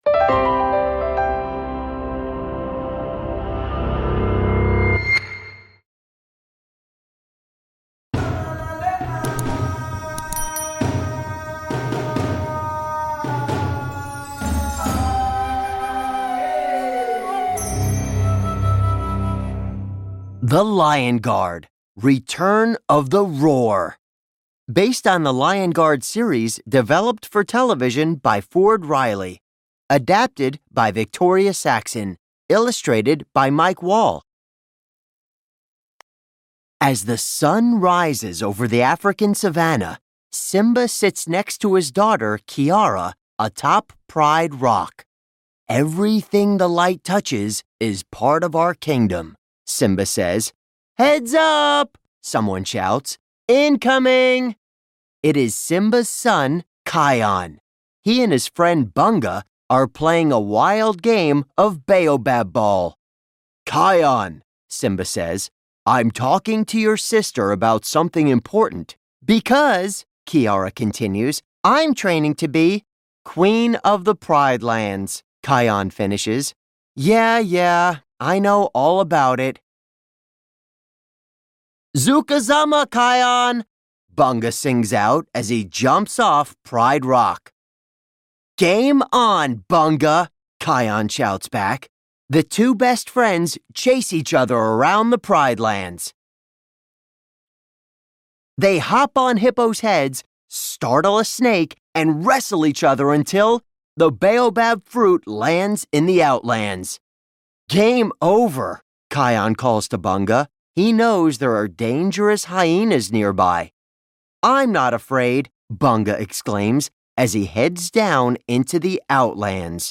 The Reigate Squire: Sherlock Holmes Solves Another Shocking Case (Audiobook)